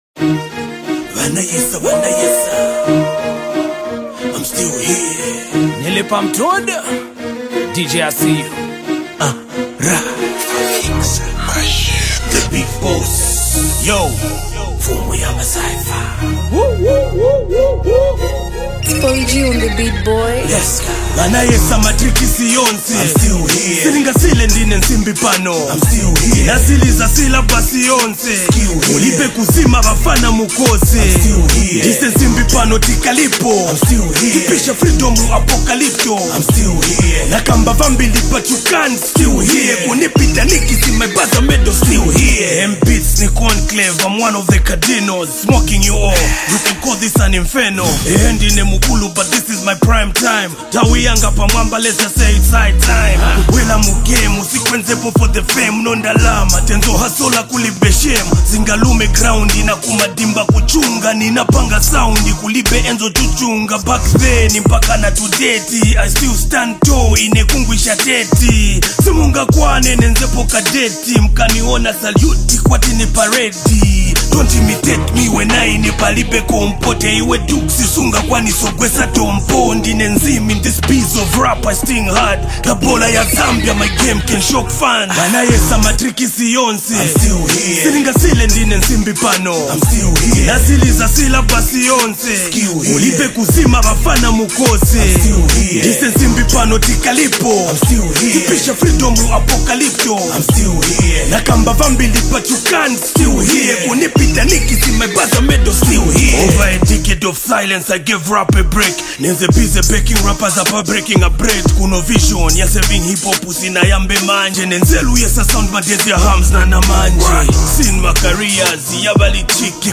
hip-hop anthem
The song carries a bold and confident tone
rap song